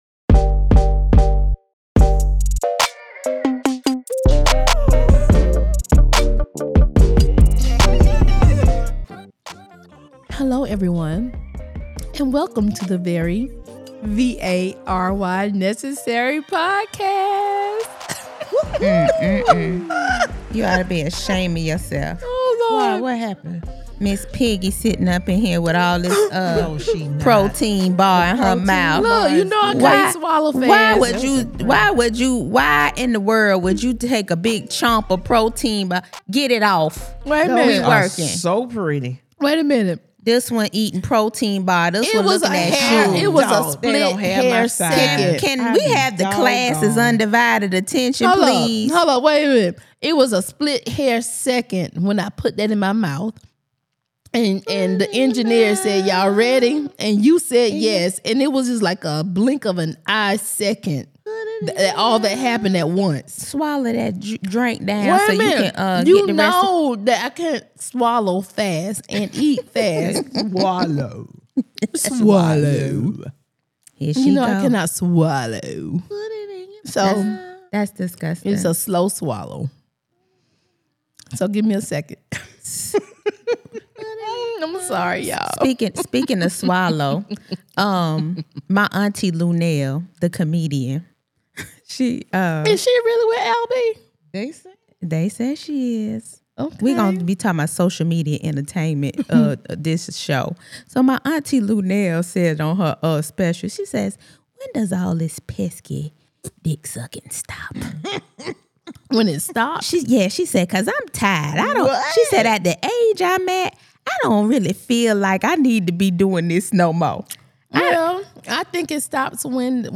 So... what has 50 Cent done now? The ladies of the VNP are diving into the latest digital war zone.